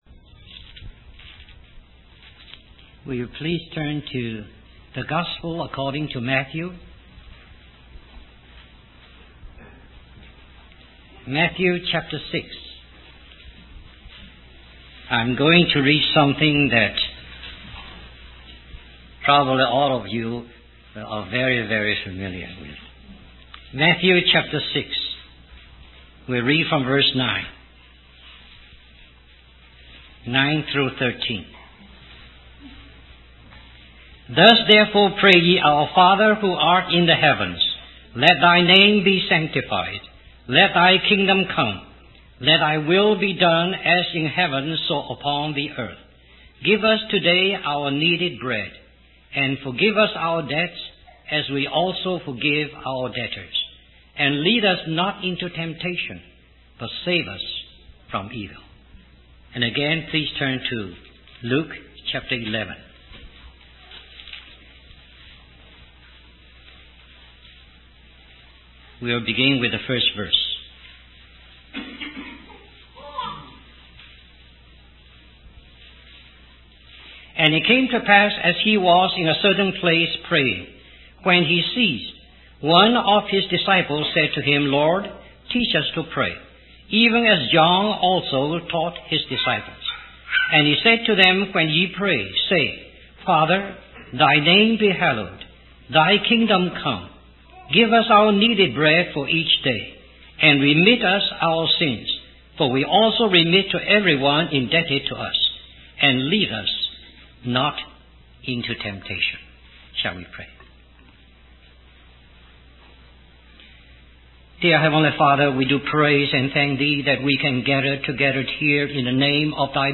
In this sermon, the speaker emphasizes the importance of prayer and the ministry of the word.